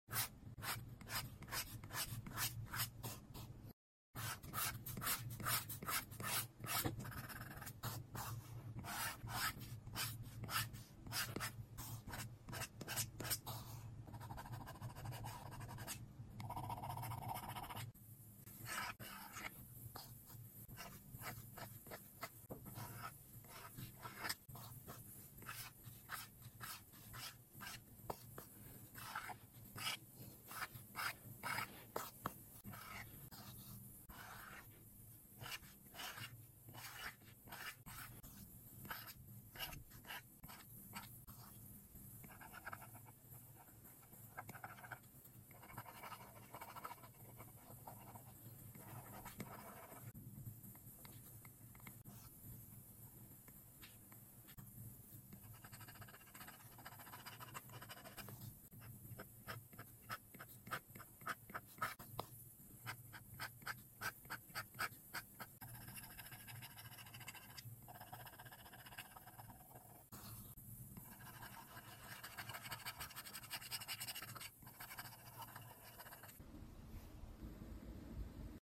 Drawing white noise, enjoy the sound effects free download
enjoy the Mp3 Sound Effect Drawing white noise, enjoy the rustling sound.